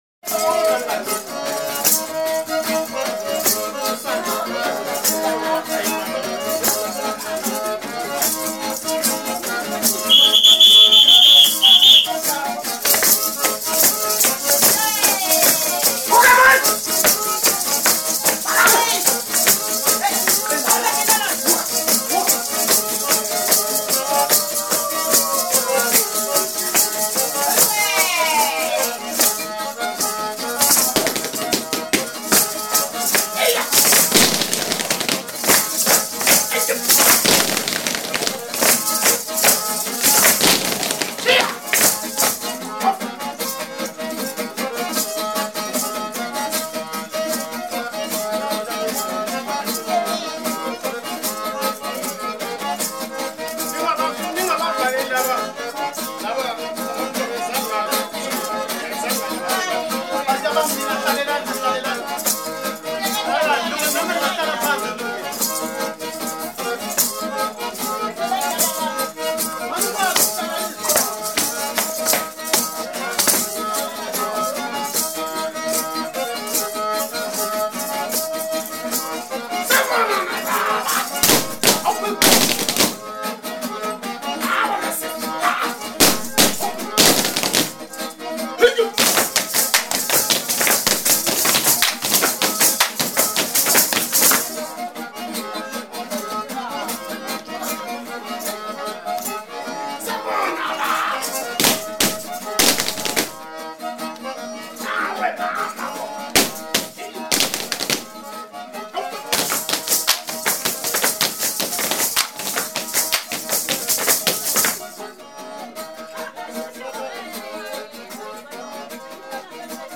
As for favourite shoe-related songs, on my desert boot island will be the wonderful Gumboot Guitar: Zulu Street Guitar Music From South Africa, on the Topic label. Gumboot is a dance – synchronised boot-slapping and heel-kicking – developed by black gold miners over a century ago. But it’s also music for guitar and concertina, with the job of maintaining the dance’s punishing tempo. Informal and full of character,
these street recordings were made in the 1980s